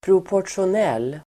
Ladda ner uttalet
proportionell adjektiv, proportional Uttal: [propor_tsjon'el:] Böjningar: proportionellt, proportionella Definition: som står i proportion till något Exempel: skatten är proportionell mot inkomsten (tax is proportional to income)